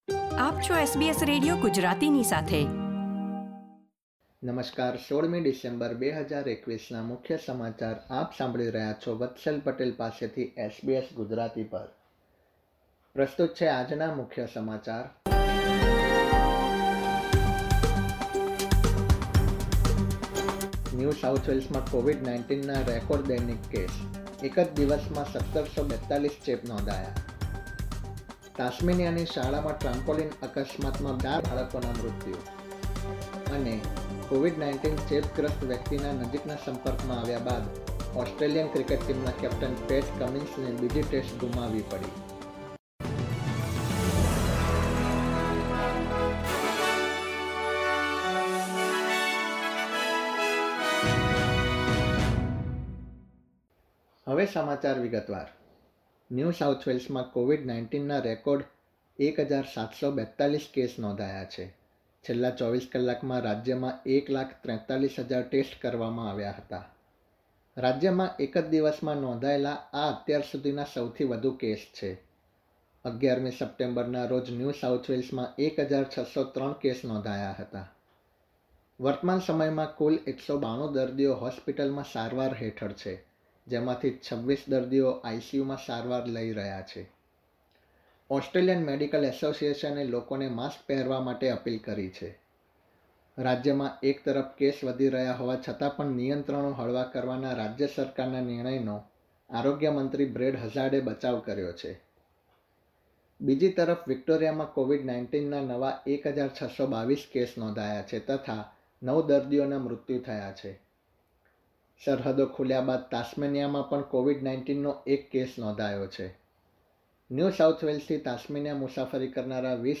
SBS Gujarati News Bulletin 16 December 2021
gujarati_1612_newsbulletin.mp3